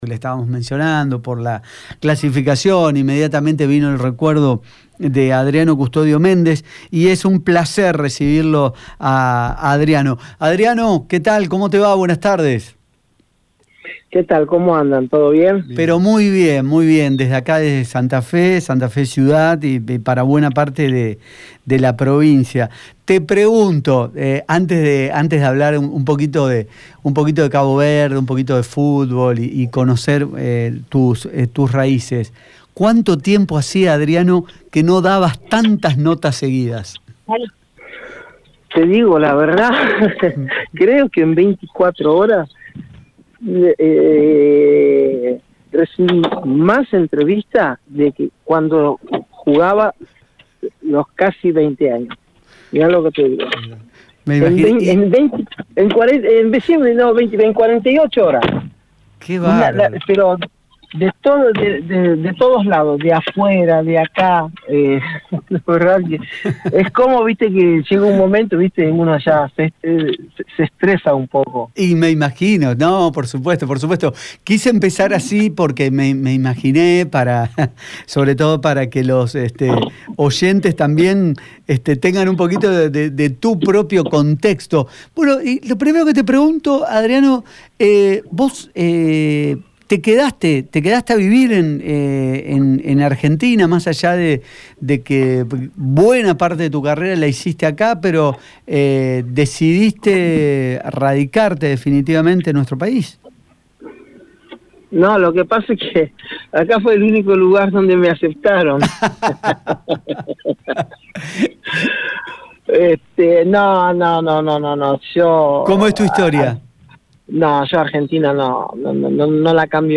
En diálogo con EME Medios, el jugador que se iniciara en Estudiantes de La Plata recordó con mucho cariño la capital provincial: «Mi Santa Fe querida», fue lo primero que dijo cuando se le consultó por su paso por Colón.